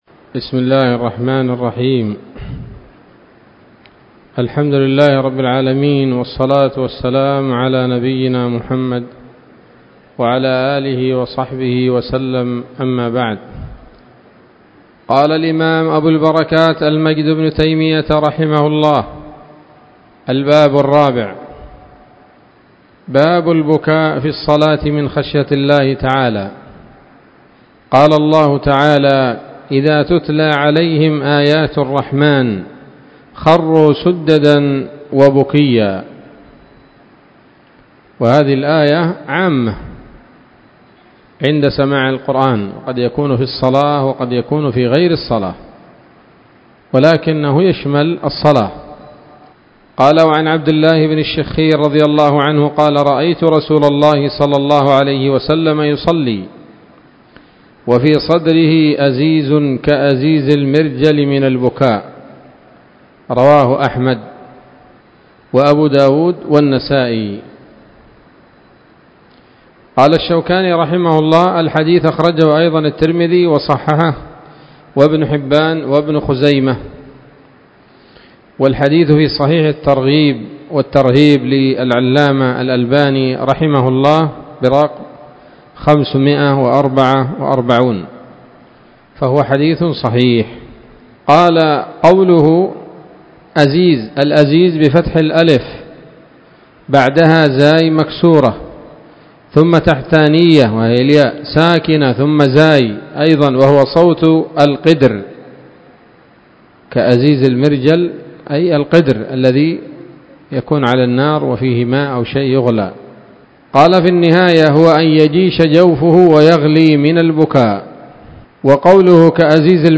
الدرس السابع من أبواب ما يبطل الصلاة وما يكره ويباح فيها من نيل الأوطار